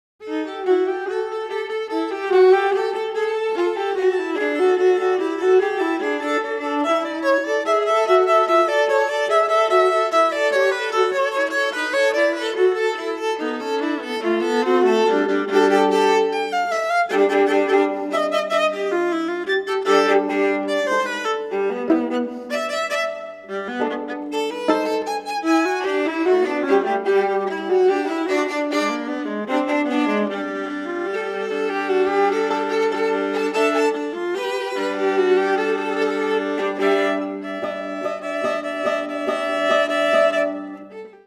18 Scherzo
Recorded at: Music Works Recordillg Studios,